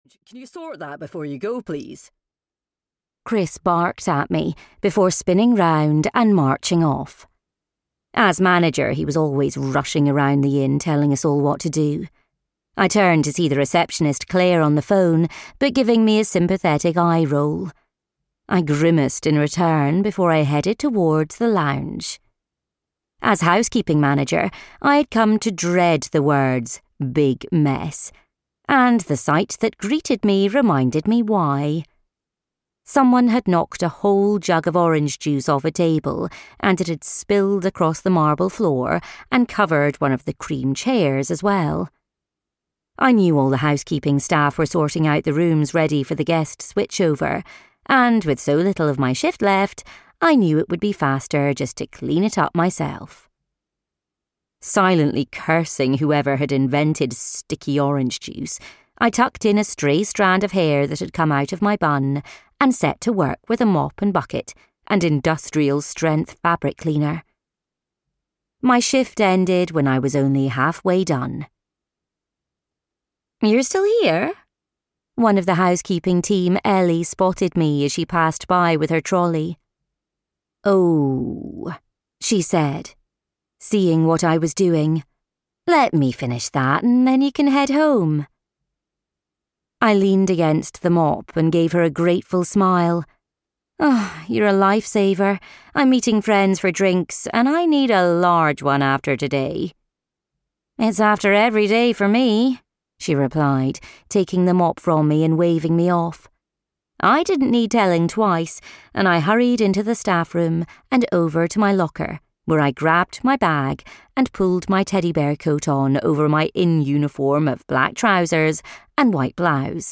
Dreams Come True at Glendale Hall (EN) audiokniha
Ukázka z knihy